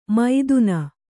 ♪ maiduna